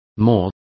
Also find out how buche is pronounced correctly.